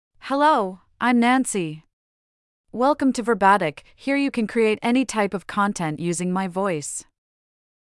Nancy — Female English (United States) AI Voice | TTS, Voice Cloning & Video | Verbatik AI
Nancy is a female AI voice for English (United States).
Voice sample
Listen to Nancy's female English voice.
Nancy delivers clear pronunciation with authentic United States English intonation, making your content sound professionally produced.